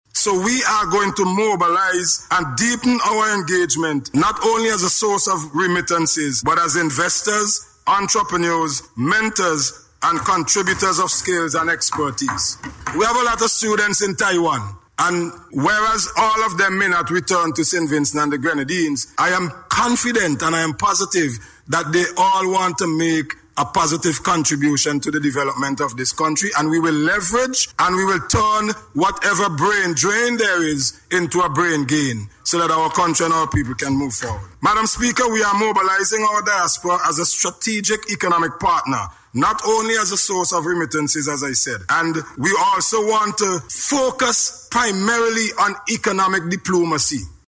The announcement was made by Minister of Foreign Affairs Hon. Fitzgerald Bramble, as he responded to a question in Parliament this week.